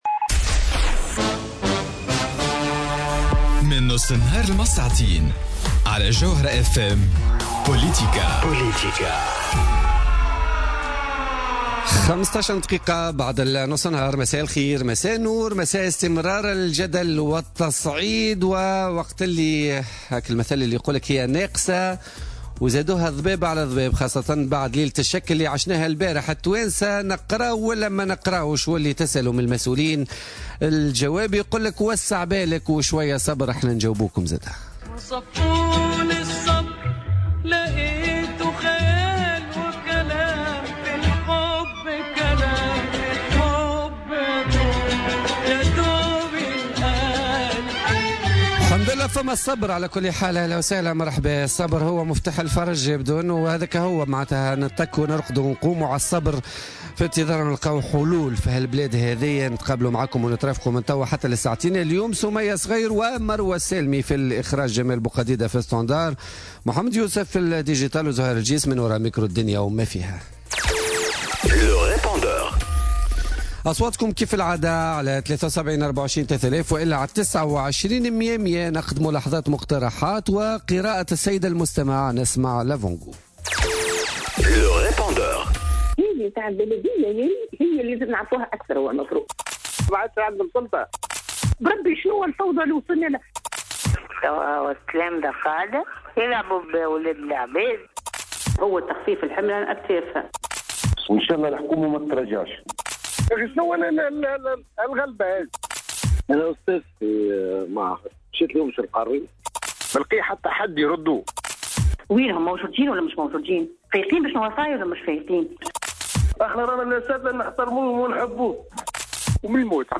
الوزير السابق الهادي بكوش في حوار خاص حول كتابه الجديد